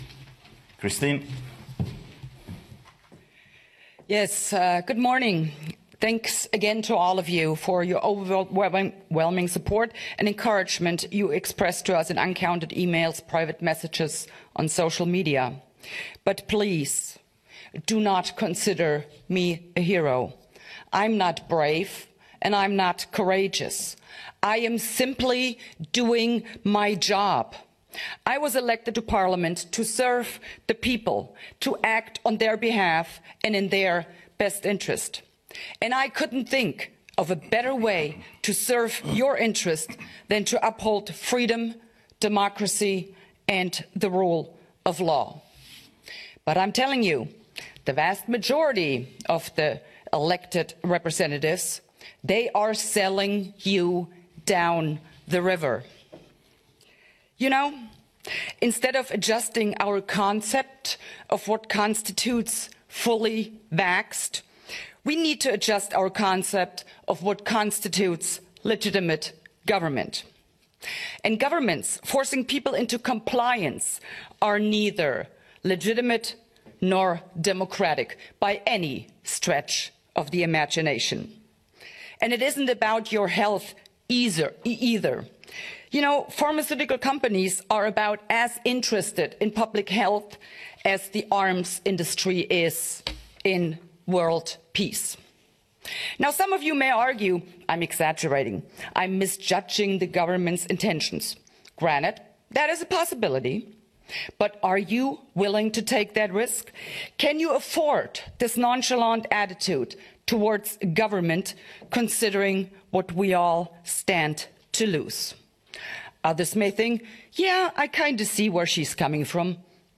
Christine Anderson EU Abgeordnete mit einer grossartigen Rede zu den ganzen Corona-Massnahmen und wohin diese uns führen werden! Sie erklärte, es habe "in der gesamten Geschichte der Menschheit noch nie eine politische Elite gegeben, die sich ernsthaft um das Wohlergehen der normalen Menschen sorgt".